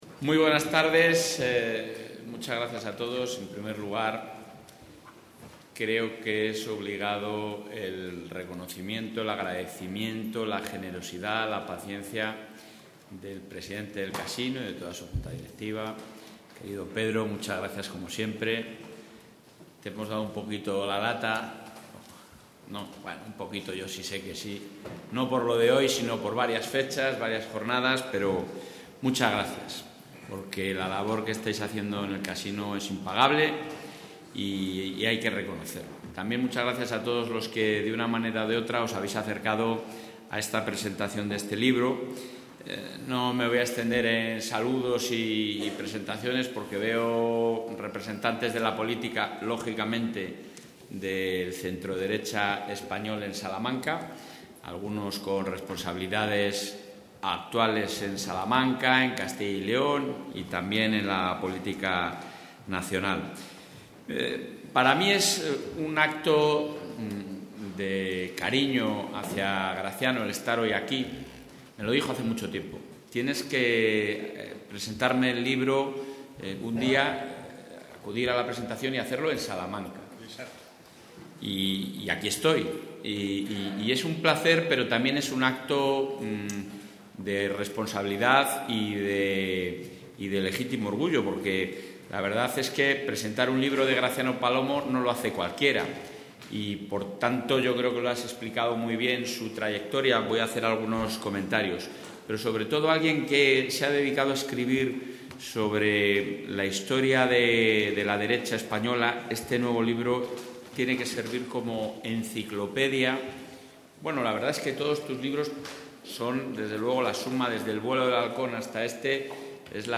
Intervención del portavoz.
Consejo de Gobierno. 12 de diciembre de 2024